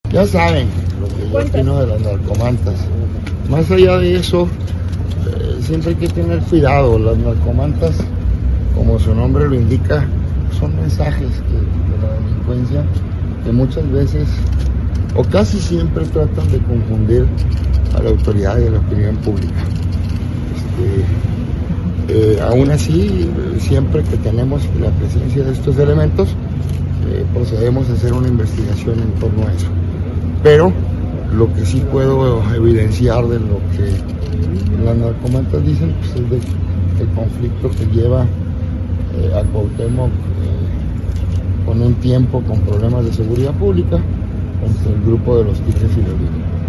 AUDIO: CÉSAR JAÚREGUI MORENO, FISCAL GENERAL DEL ESTADO (FGE)